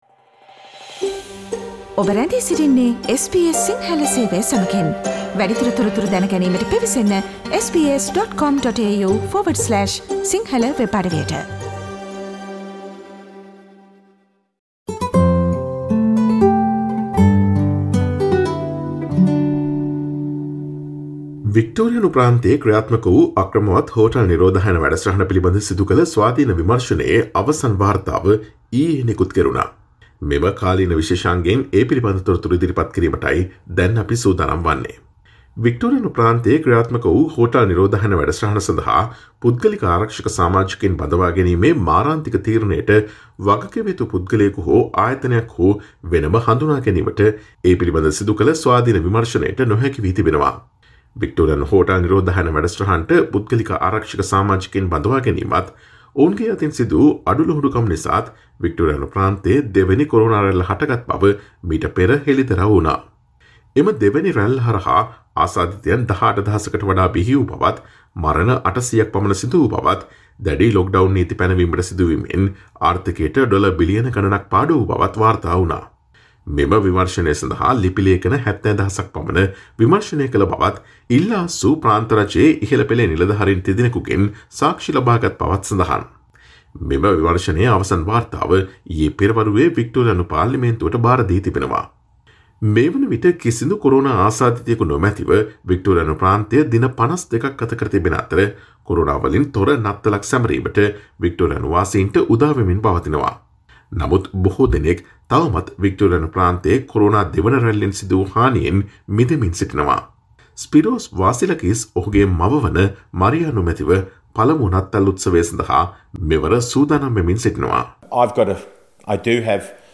SBS Sinhala Feature on the final report of the Victorian Hotel Quarantine inquiry.